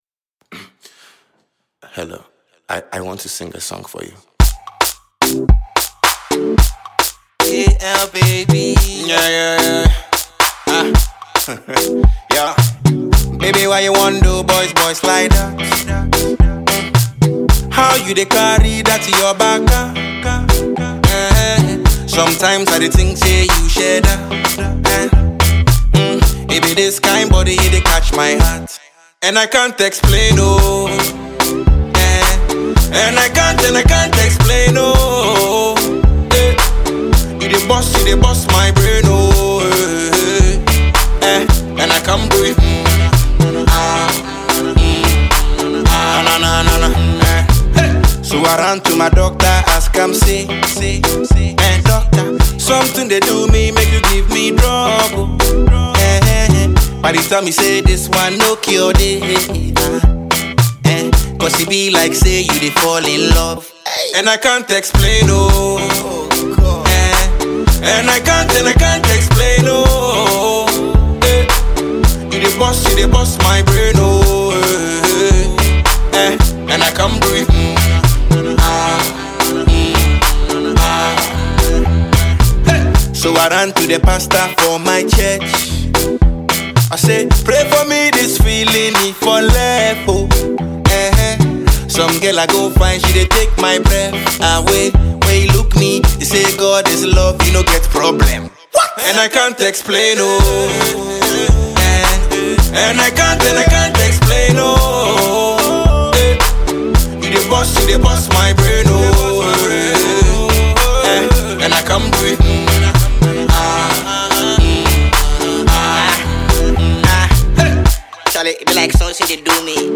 singer and rapper